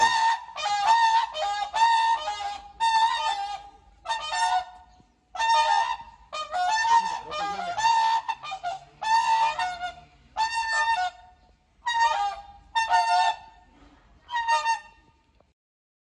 大天鹅求偶时歌声
水边一群大天鹅引颈鸣叫 大天鹅叫声 单调粗哑似喇叭声